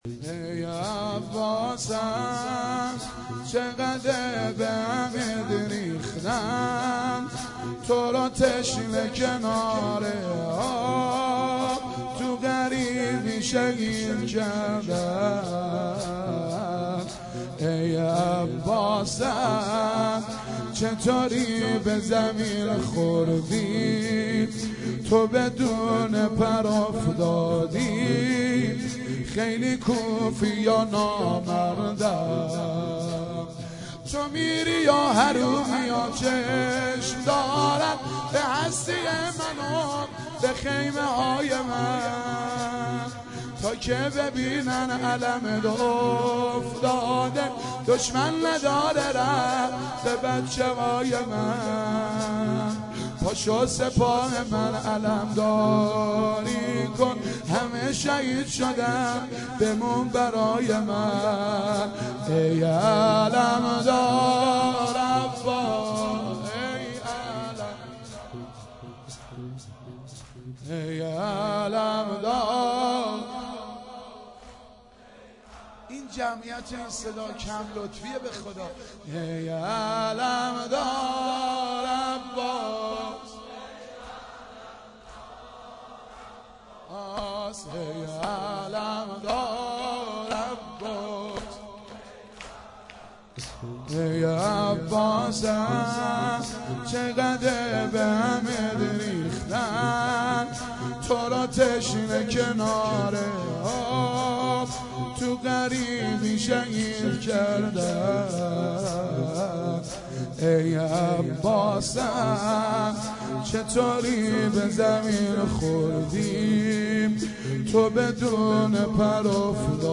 روز تاسوعا محرم ۱۳۹۷ – پایگاه اطلاع رسانی مسجد و حسینیه جامع حضرت فاطمه الزهرا اصفهان
روز تاسوعا محرم ۱۳۹۷
سینه زنی بخش اول